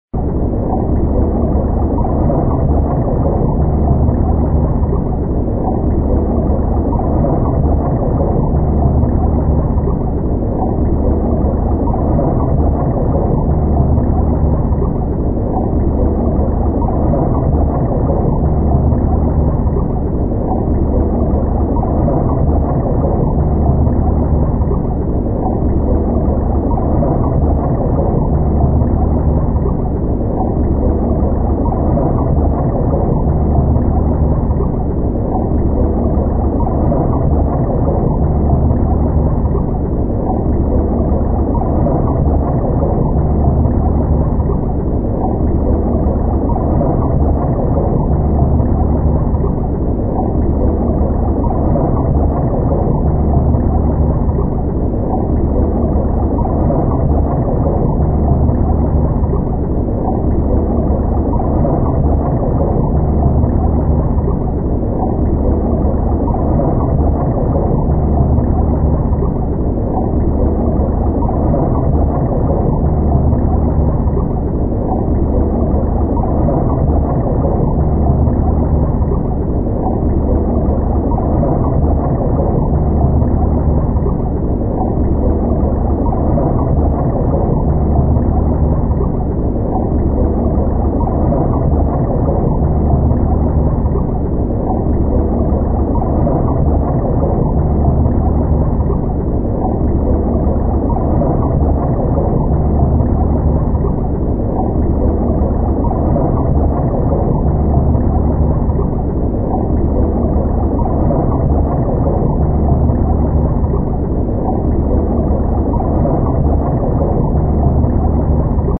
На этой странице собраны звуки войны и перестрелок — тревожные, резкие, напоминающие о разрушениях.
Звук подводной лодки на морском дне